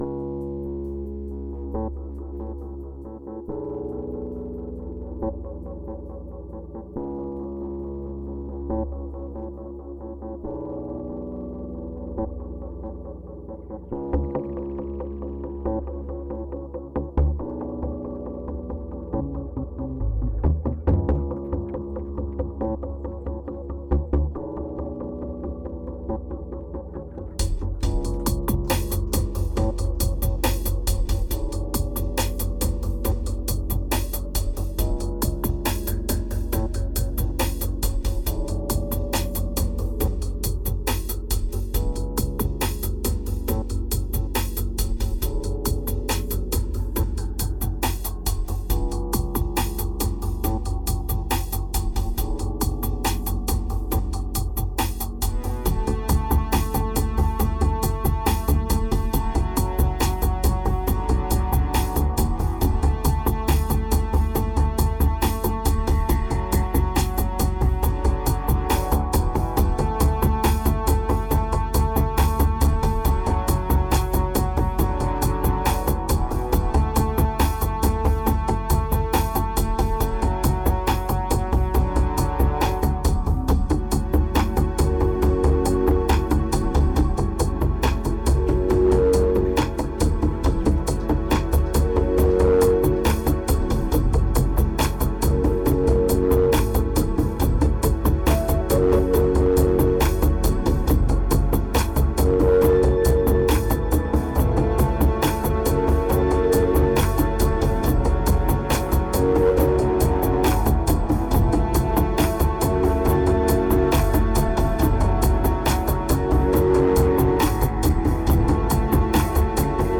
2188📈 - 7%🤔 - 69BPM🔊 - 2012-05-13📅 - -114🌟